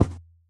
Minecraft Version Minecraft Version snapshot Latest Release | Latest Snapshot snapshot / assets / minecraft / sounds / mob / camel / step3.ogg Compare With Compare With Latest Release | Latest Snapshot
step3.ogg